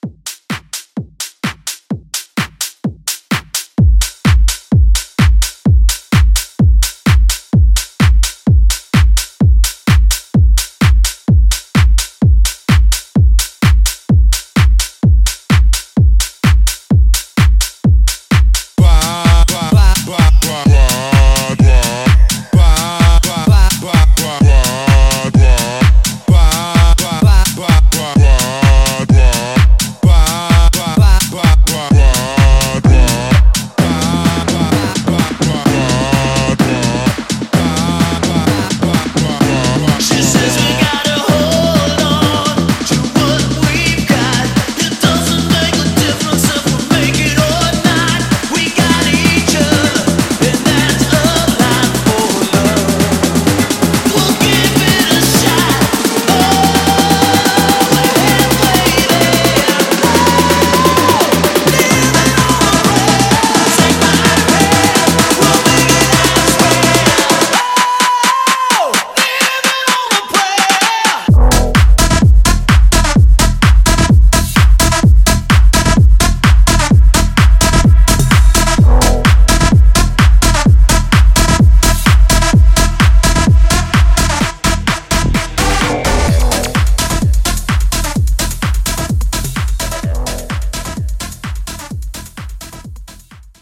Genres: 80's , RE-DRUM
Clean BPM: 112 Time